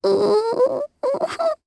Rodina-Vox_Sad_jp.wav